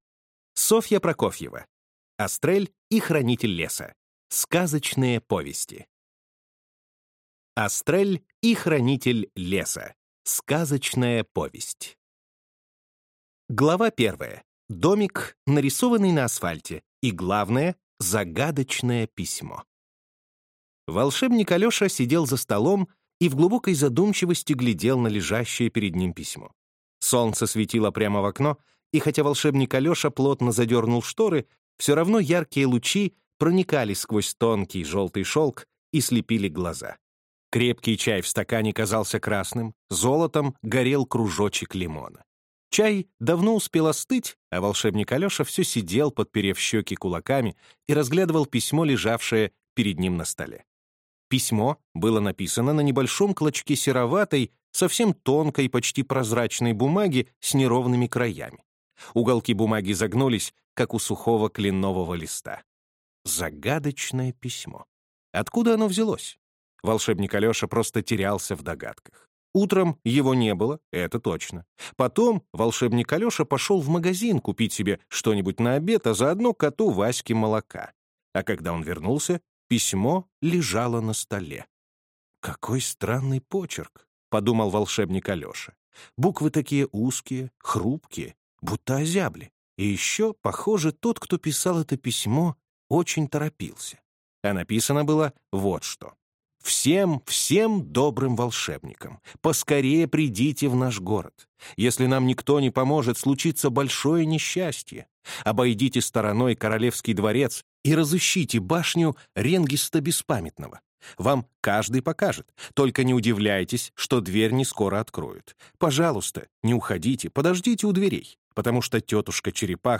Аудиокнига Астрель и Хранитель леса. Маленькая принцесса | Библиотека аудиокниг